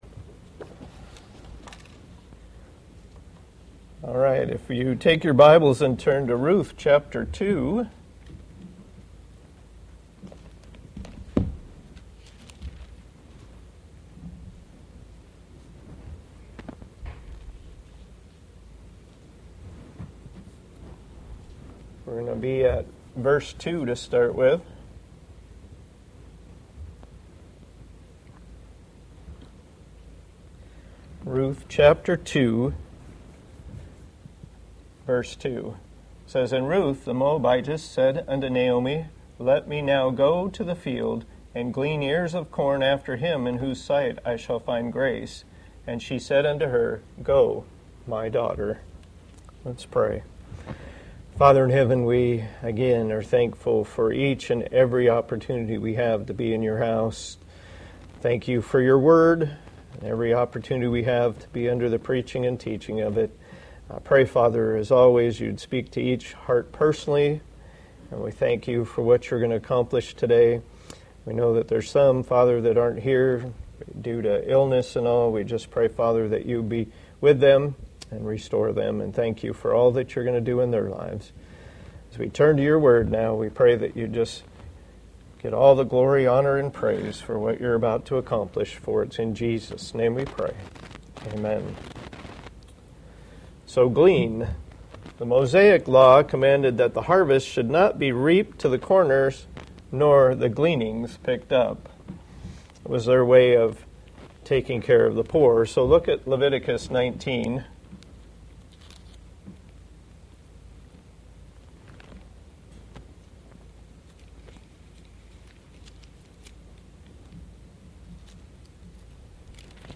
Passage: Ruth 2:2 Service Type: Sunday School